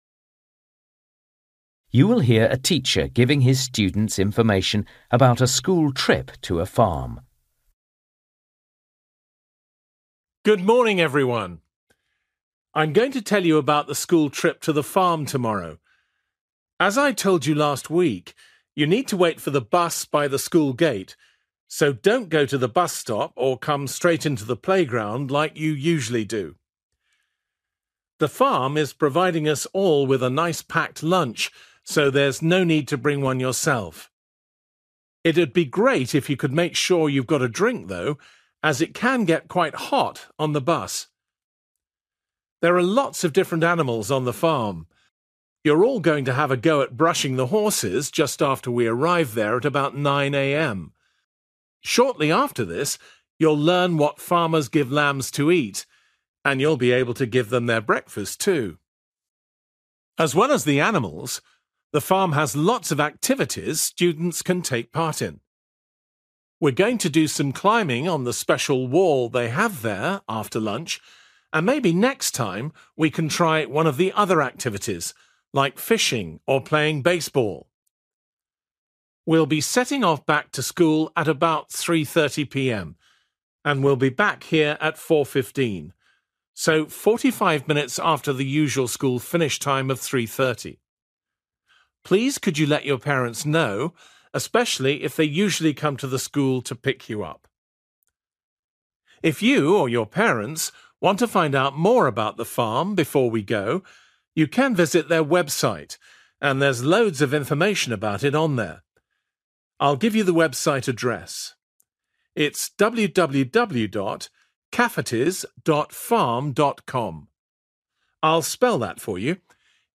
You will hear a teacher giving his students information about a school trip to a farm.